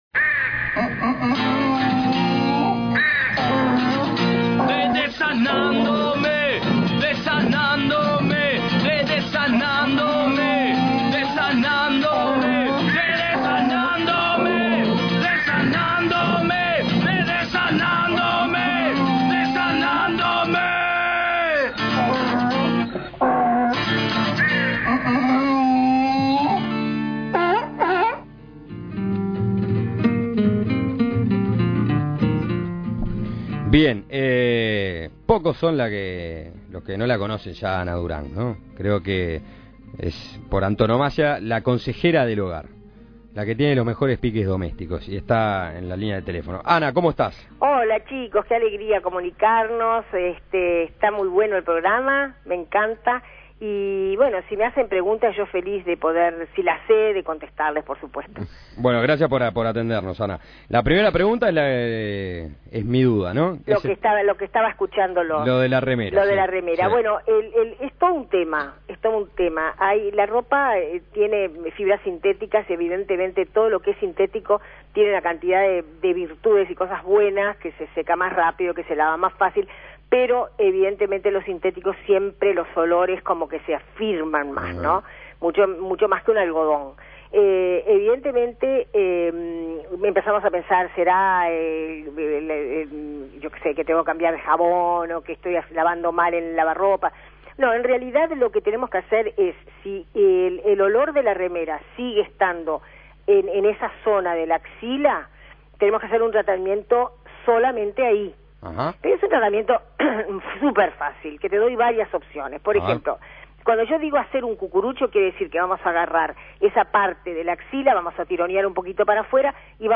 Además, contestó preguntas de los oyentes.